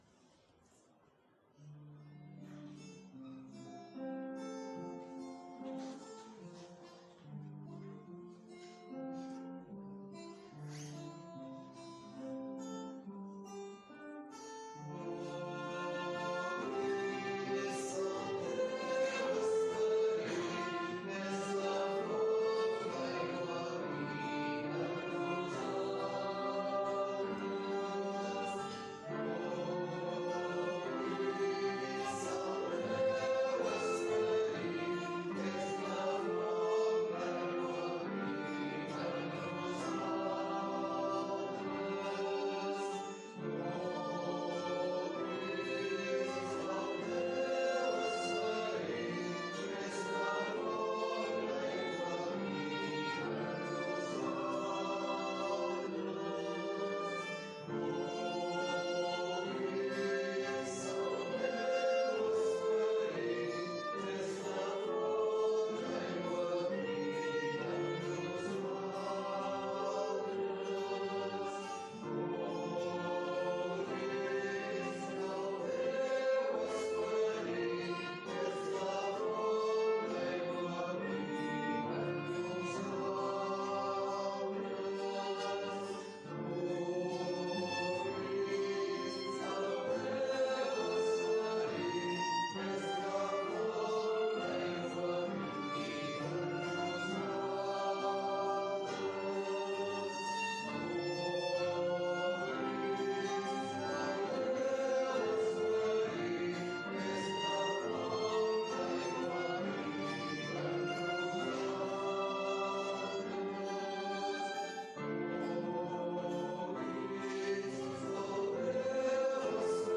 Pregària de Taizé a Mataró... des de febrer de 2001
Parròquia de Maria Auxiliadora - Diumenge 24 de novembre de 2019
Vàrem cantar...